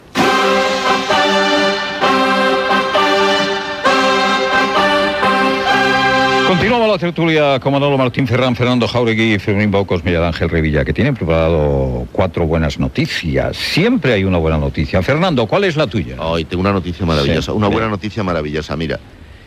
Sintonia i entrada de la tertúlia de "Protagonistas".
Info-entreteniment
FM